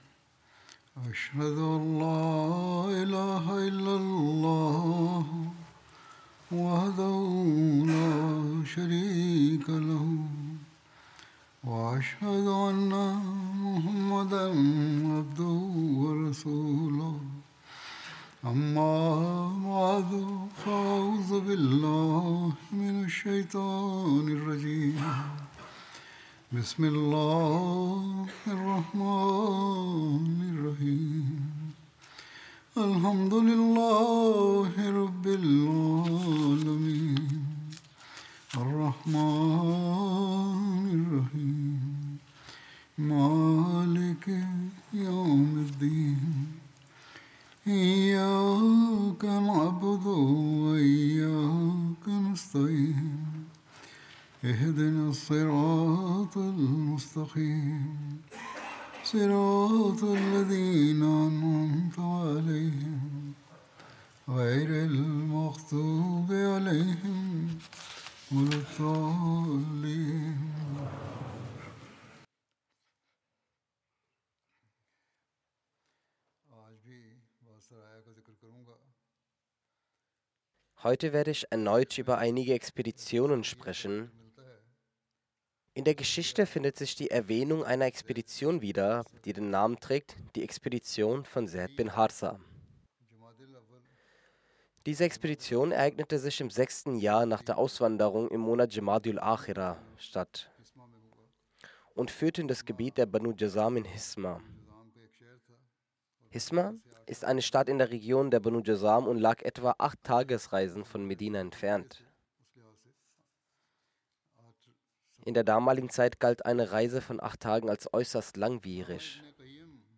German Friday Sermon by Head of Ahmadiyya Muslim Community
German Translation of Friday Sermon delivered by Khalifatul Masih